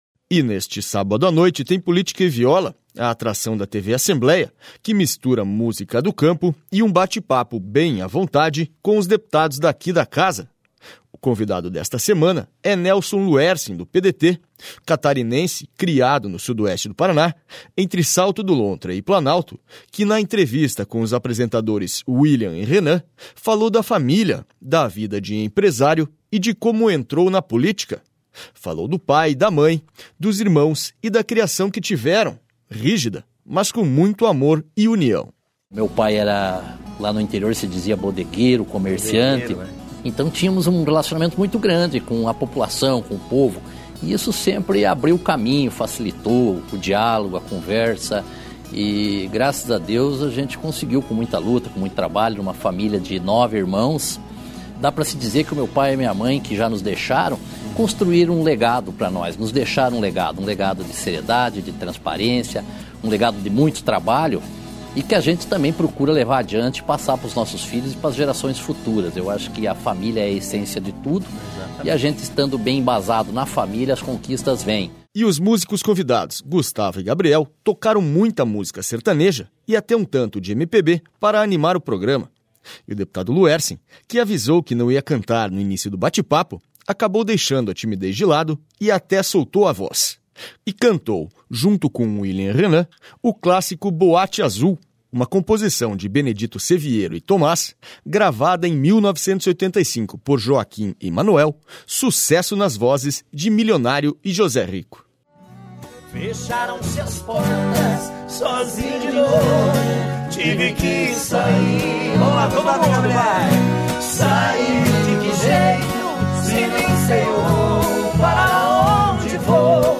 Nelson Luersen solta a voz neste sábado no Política e Viola
E neste sábado à noite tem Política e Viola, a atração da TV Assembleia que mistura música do campo e um bate papo bem à vontade com os deputados daqui da casa.// O convidado desta semana é Nelson Luersen, do PDT, o catarinense criado no Sudoeste do Paraná, entre Salto do Lontra e Planalto, que na...